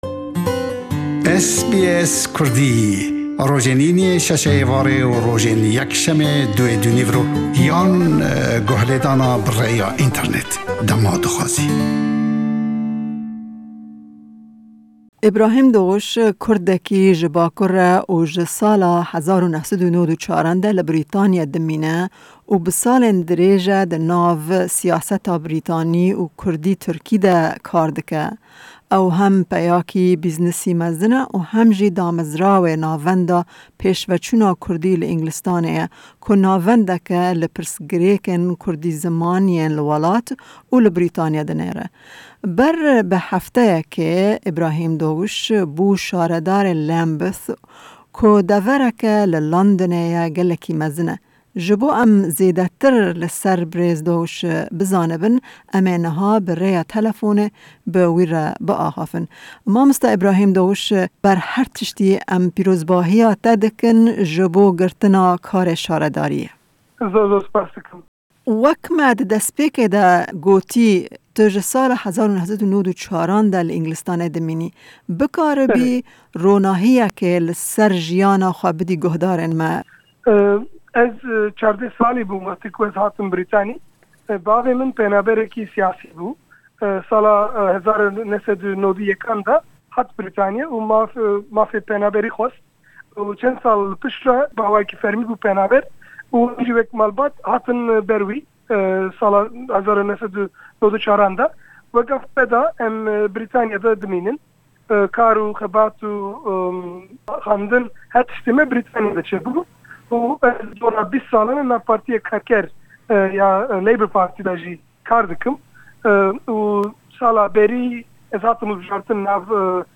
Ber bi hefteyekê berêz Ibrahim Doguş bû şaredarê Lambeth ku devereke li Lendinê ye gelekî mezine. Berêz Doguş di hevpeyvînê de ji me re behsa jiyana xwe dema zarokekî 14 salîn bû gihîştin Înglistanê dike, têkiliya wî bi civakê re û herweha behsa çawa bû Şaredarê Lambeth dike.